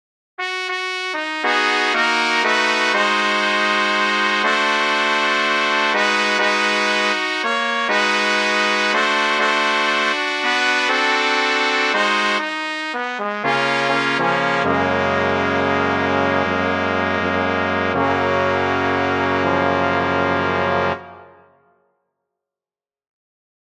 Key written in: F# Major
How many parts: 4
Type: Barbershop
All Parts mix: